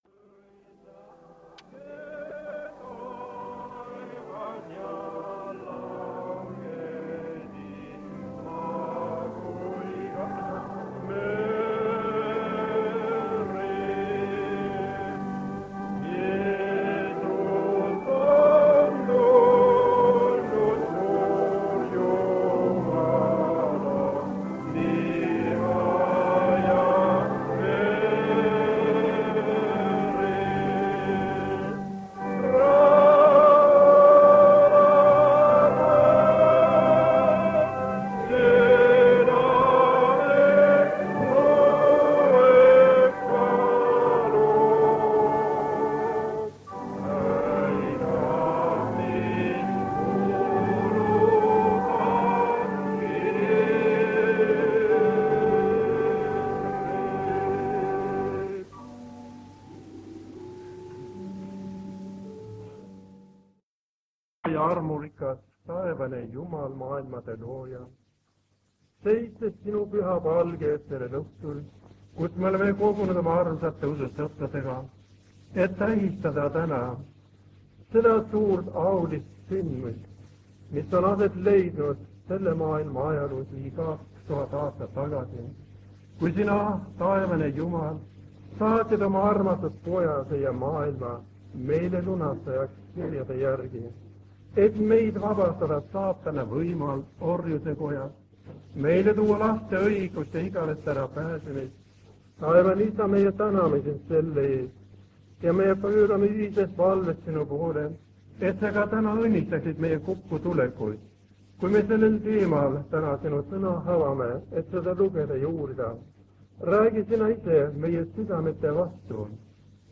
Koosolekute helisalvestused
Tallinna adventkoguduses on jõuluteenistus. Palju muusikat ja laule.
Lindistus on pärit vanalt lintmaki lindilt.